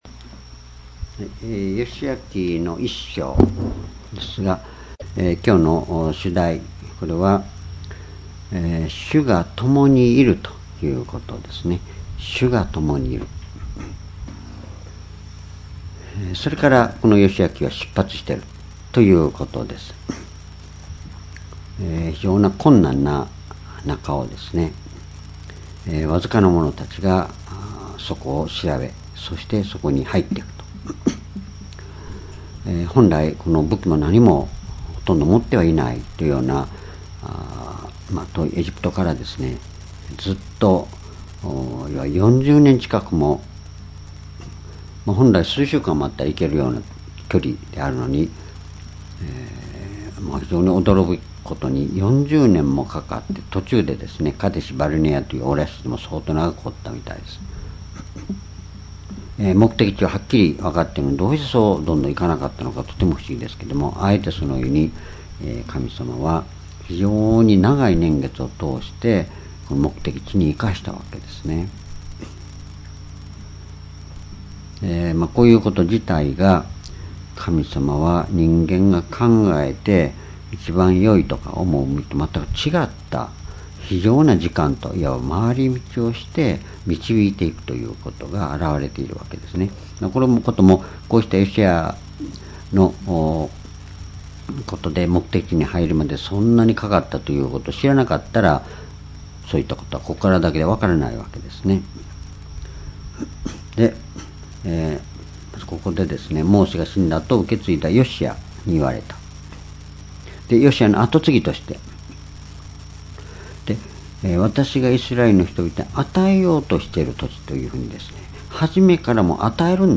主日礼拝日時 2018年9月4日 夕拝 聖書講話箇所 「主が共にいる」 ヨシュア記1章 ※視聴できない場合は をクリックしてください。